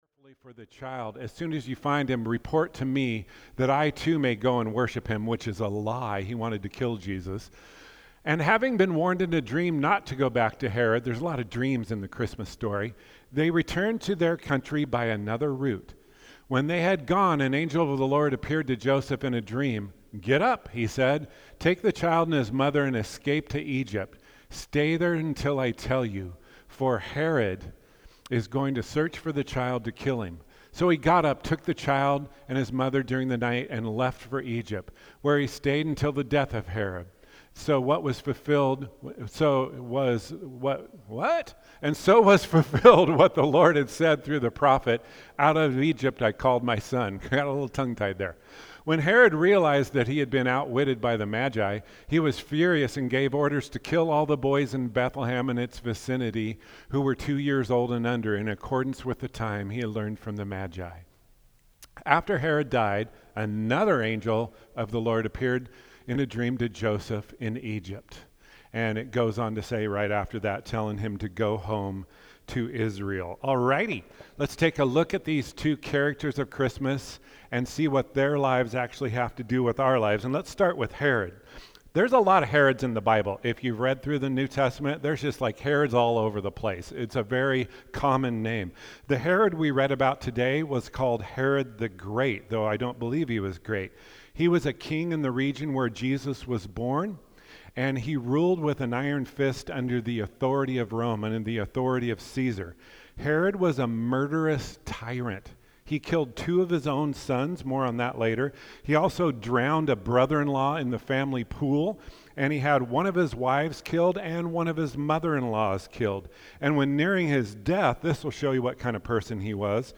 Sermons | Faith Avenue Church
We apologize, the beginning is cut off.